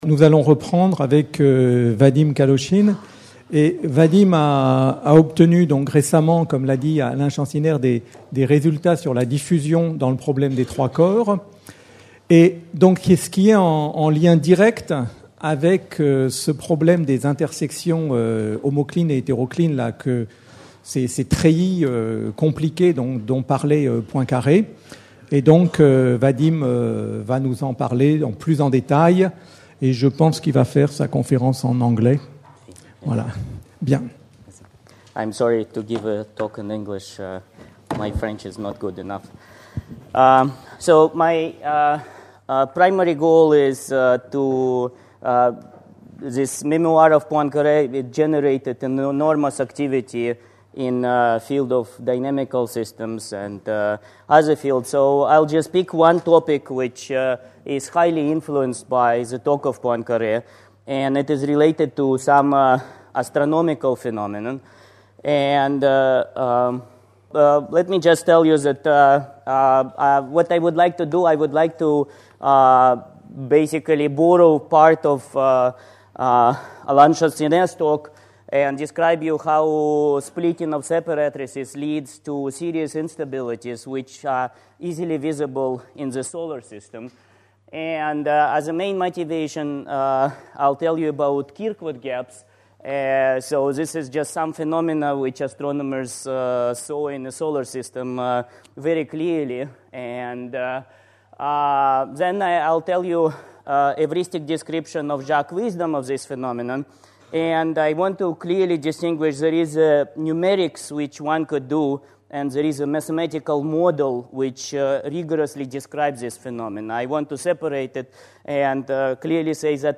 Journée de commémoration du centenaire de la disparition de Henri Poincaré (29 avril 1854 - 17 juillet 1912), organisée à l'Institut d'Astrophysique de Paris le 9 Juillet 2012.